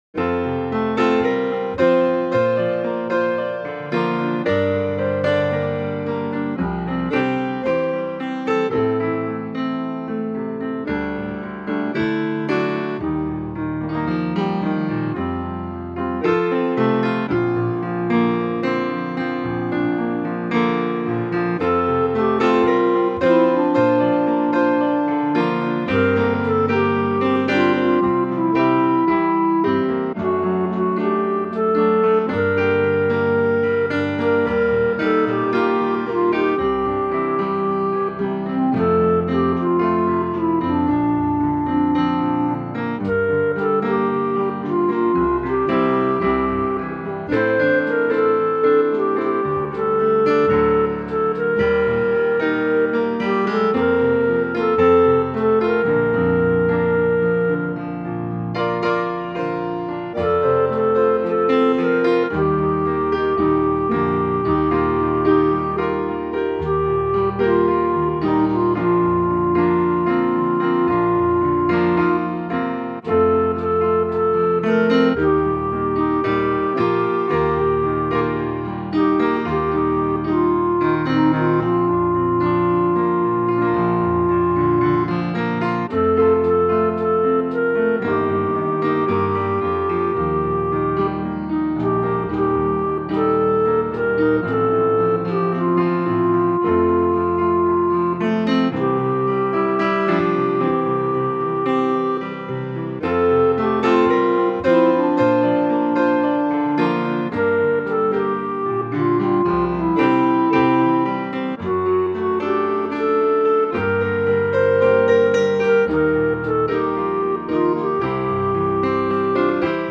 instrumental versions